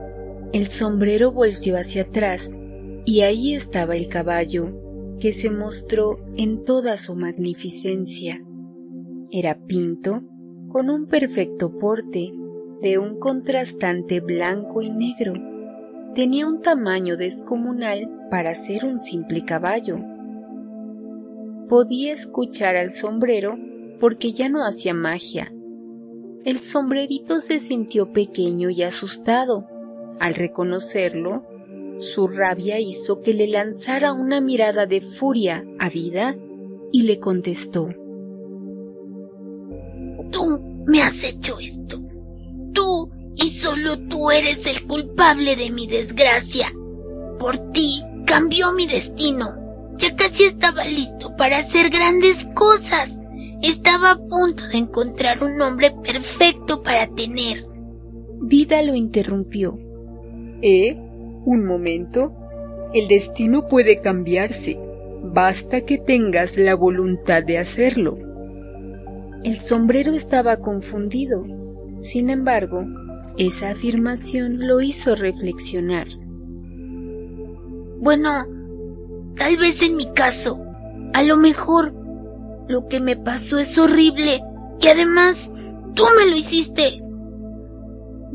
Fragmento del Audio Libro: "El Sombrero Puntiagudo"